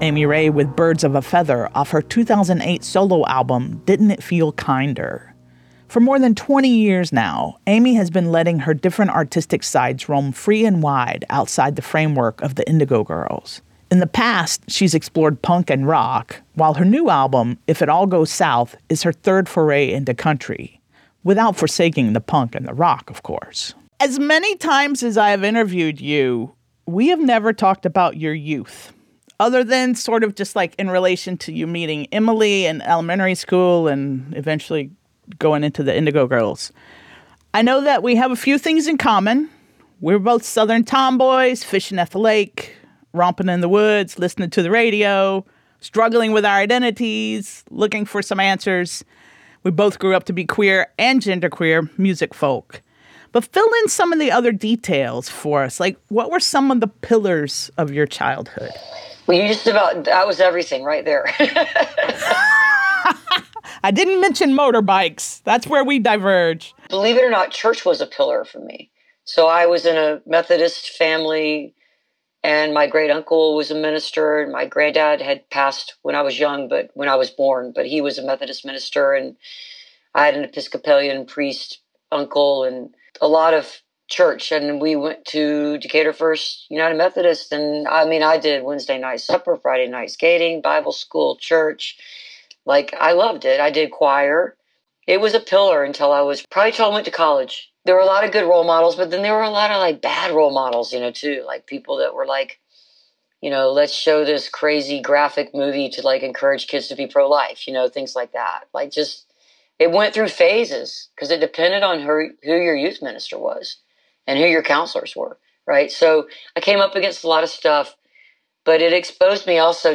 (captured from webcast)
04. interview with amy ray (3:40)